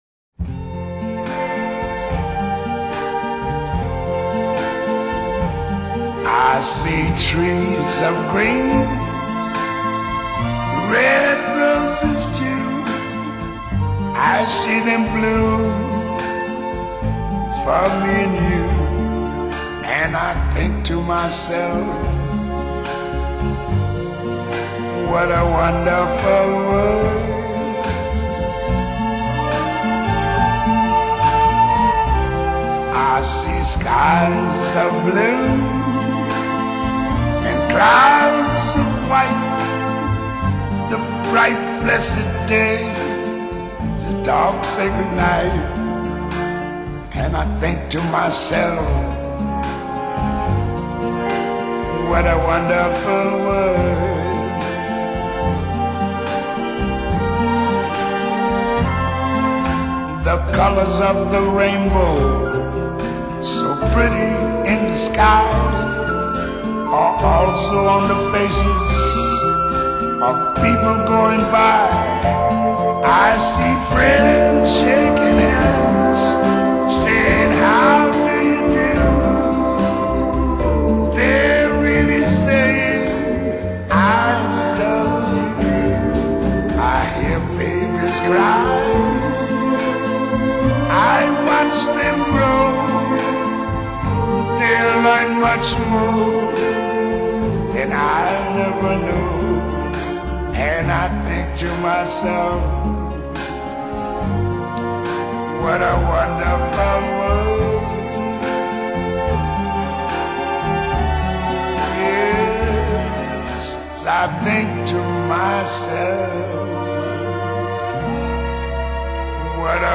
USA Genre: Jazz Year